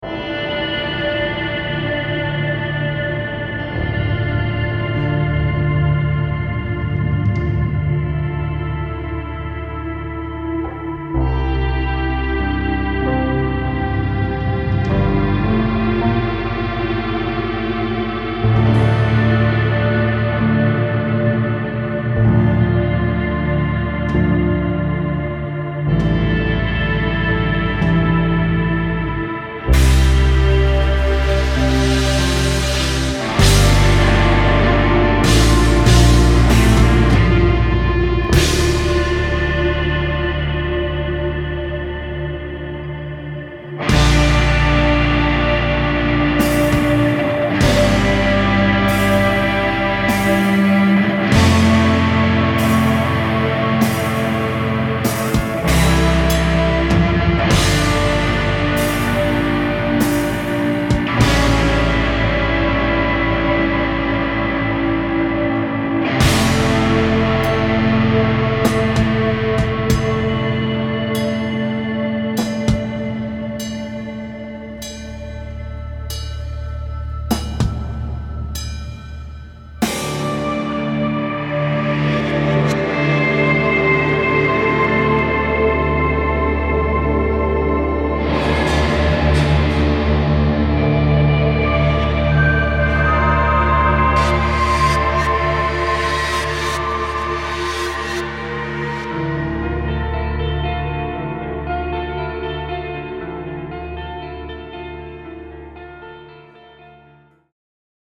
ROCK/METAL